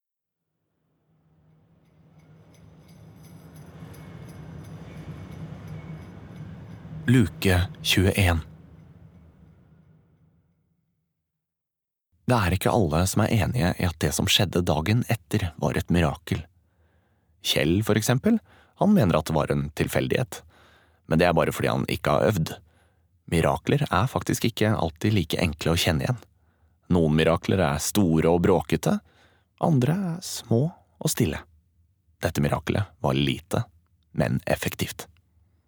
Snøfall 21. desember (lydbok) av Hanne Hagerup